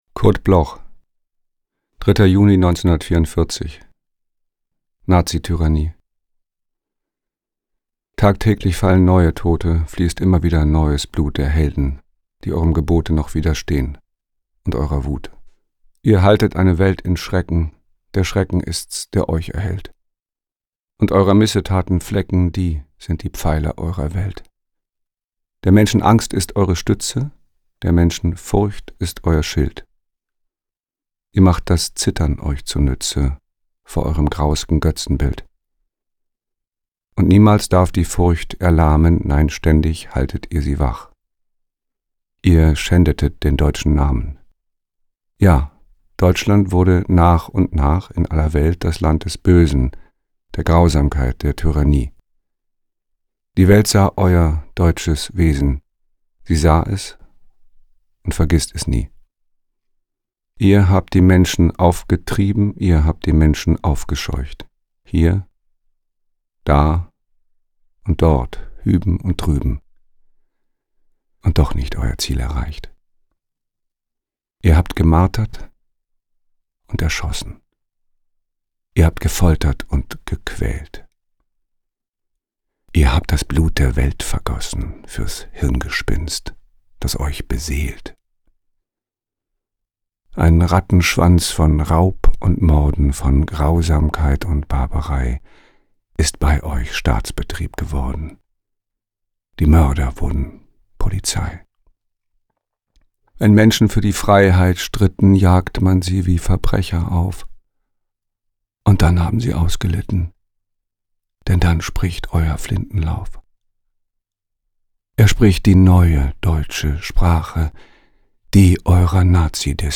voorgedragen door Stephan Kampwirth